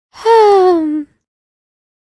shopkeep-sigh.ogg